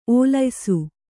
♪ ōlaysu